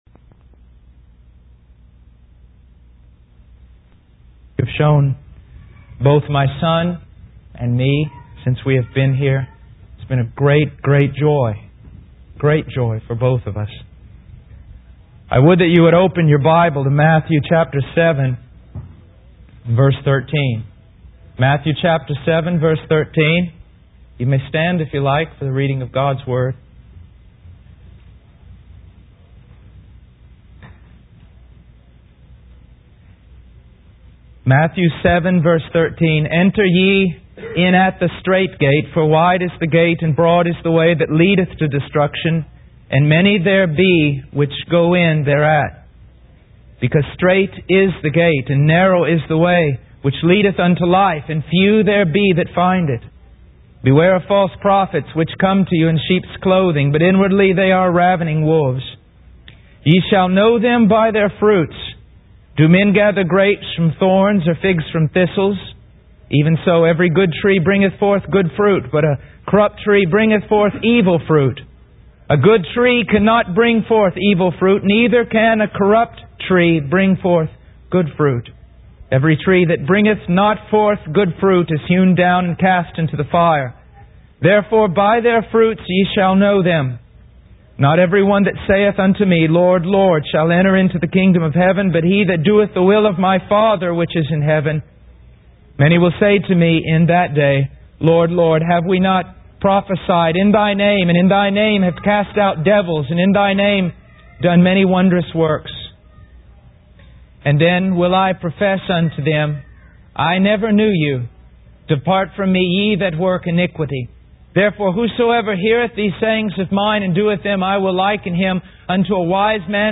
In this sermon, the preacher emphasizes the importance of entering through the straight gate, which represents Jesus Christ as the only way to heaven.